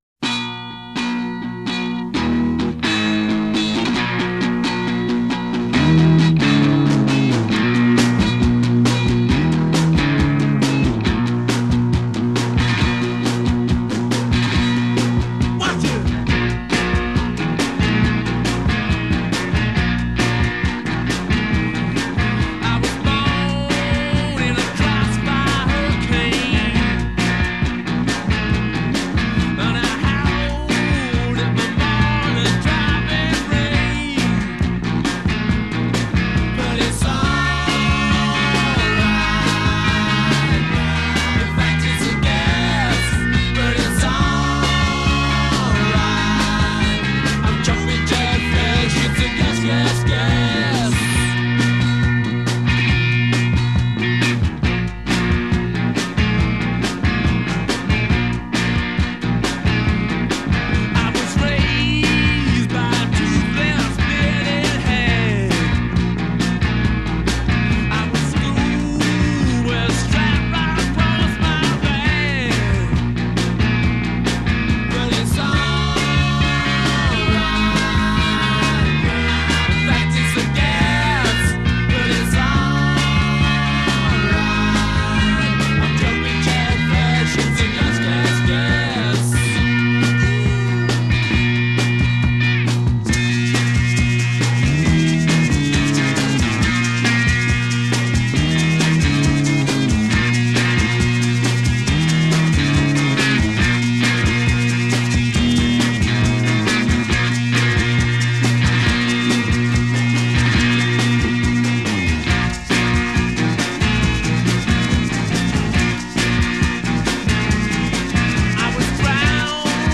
Intro 9+4 Begin with obscured guitar sound; add ensemble
Refrain 10 Two-part harmonies. b
Verse 11 Guitar solo: simple but rhythmic lines.
Add maracas.
Coda 12+ Repeat hook 6x b'